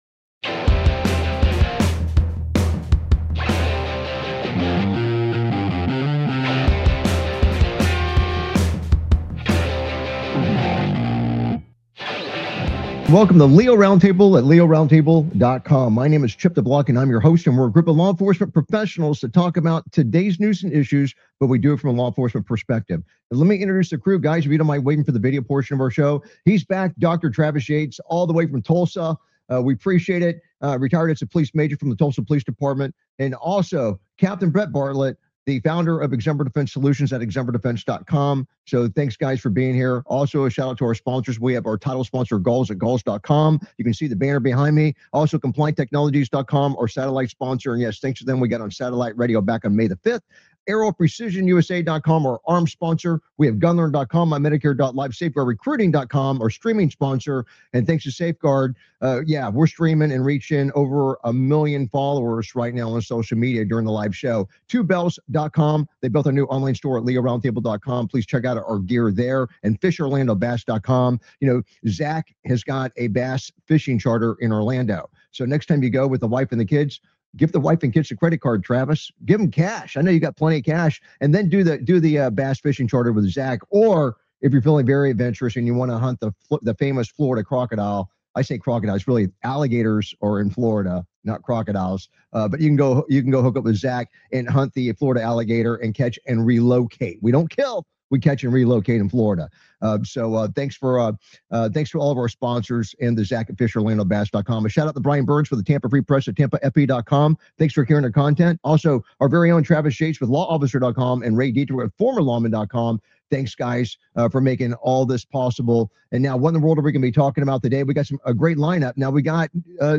LEO Round Table Talk Show